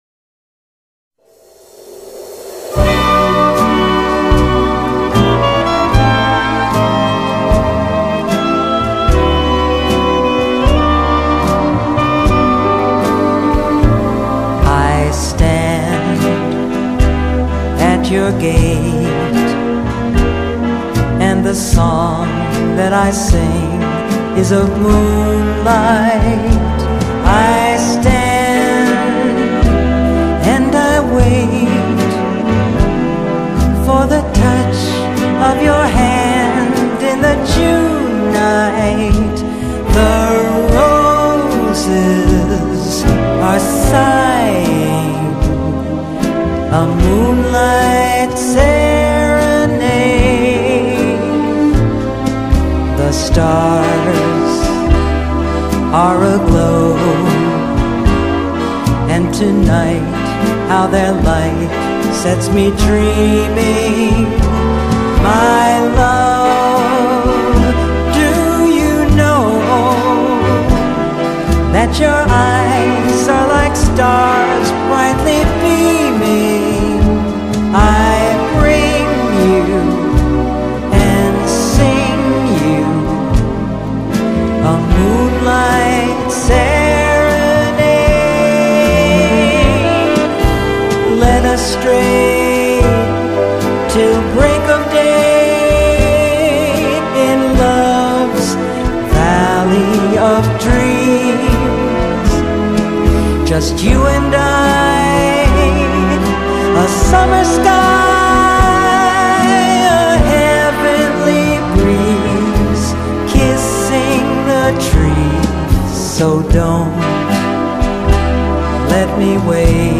還有輕爵士高雅氣息帶出的